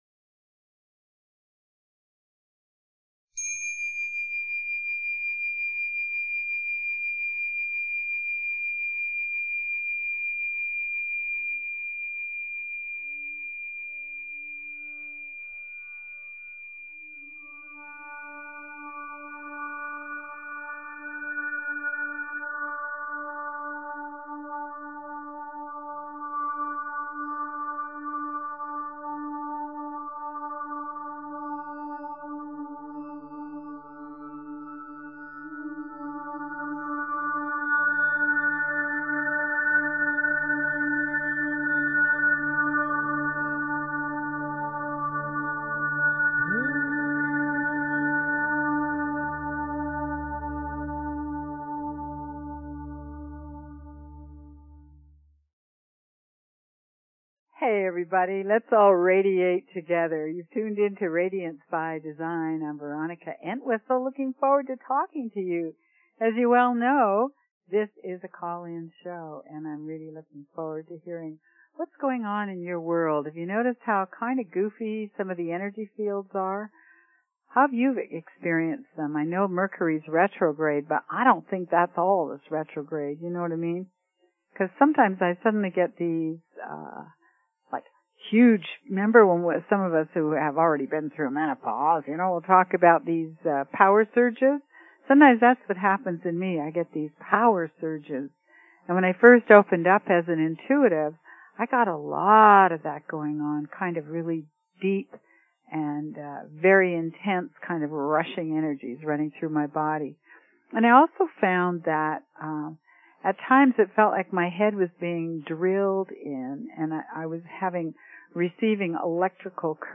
Talk Show Episode, Audio Podcast, Radiance_by_Design and Courtesy of BBS Radio on , show guests , about , categorized as
During the show, callers (and listeners) can feel the transformative energy directly through the airwaves.